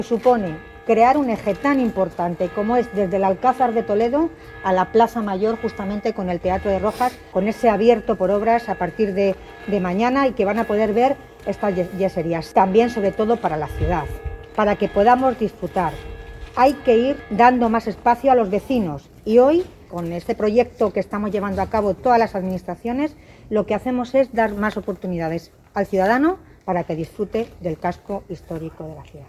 AUDIOS. Milagros Tolón, alcaldesa de Toledo
milagros-tolon_corral-de-don-diego_1.mp3